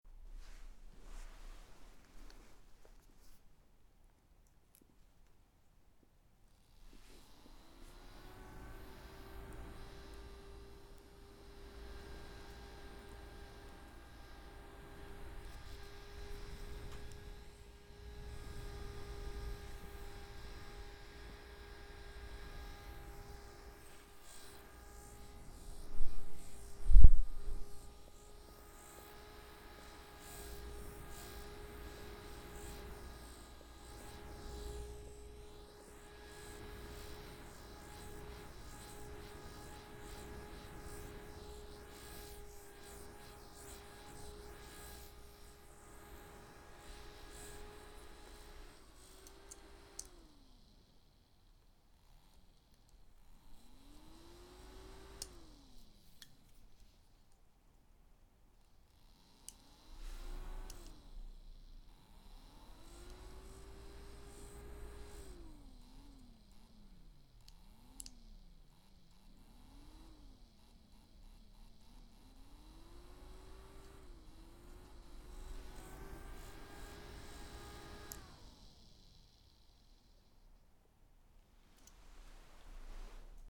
Fan-Noise-02.mp3